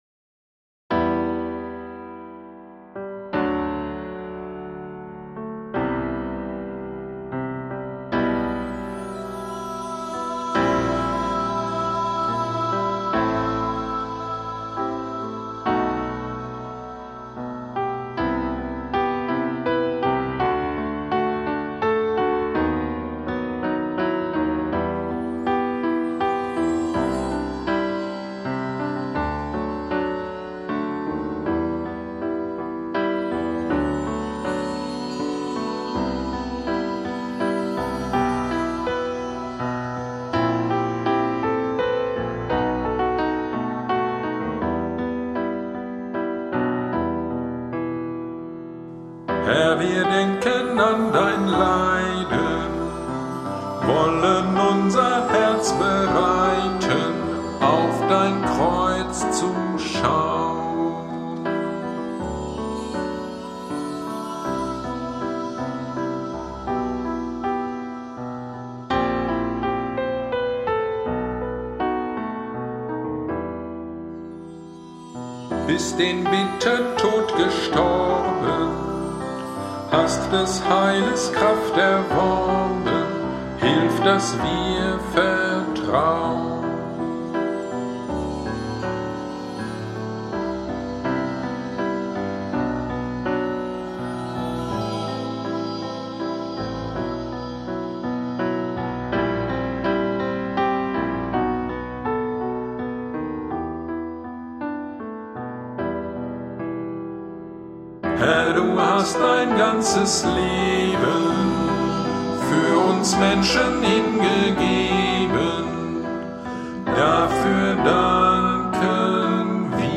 GD am 03.04.26 (Karfreitag) Predigt zu Matthäus 27,42a